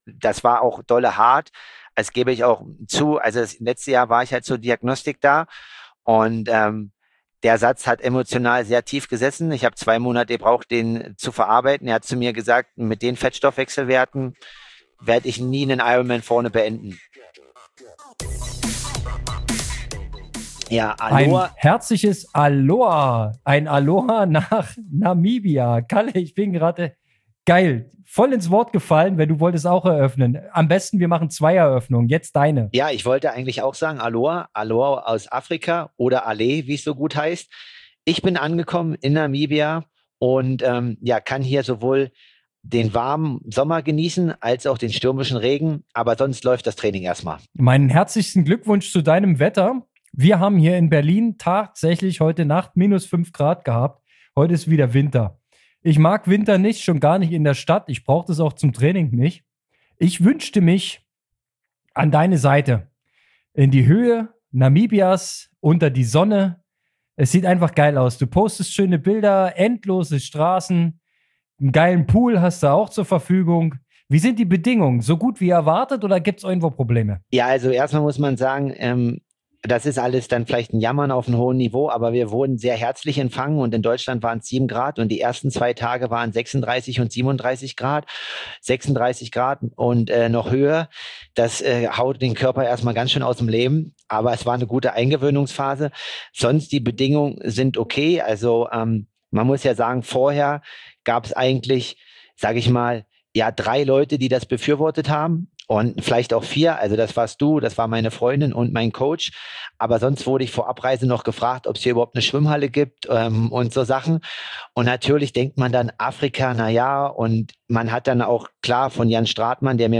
Triathlon Training im Dialog